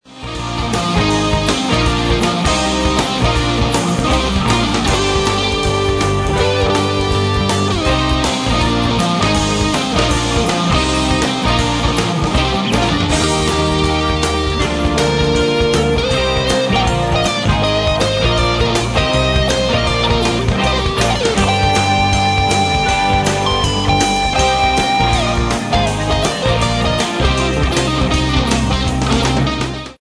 nice melodies that goes from rock/pop to jazz to blues